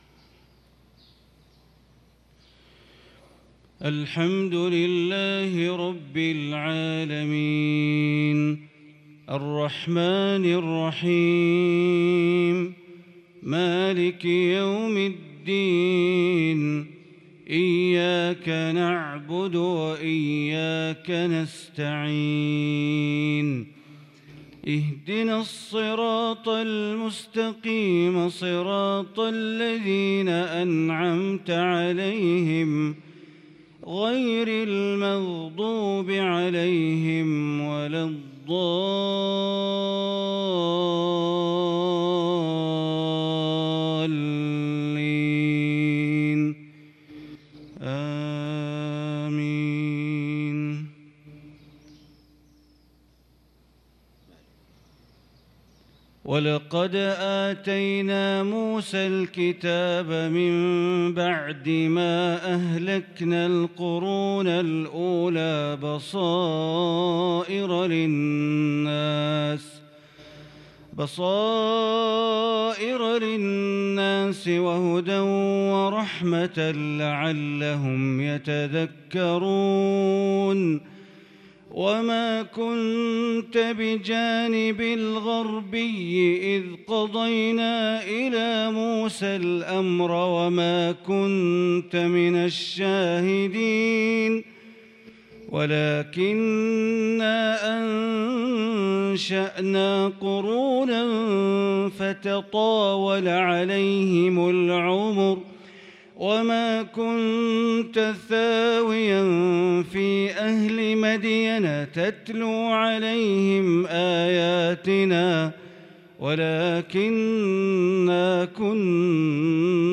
صلاة الفجر للقارئ بندر بليلة 2 صفر 1443 هـ
تِلَاوَات الْحَرَمَيْن .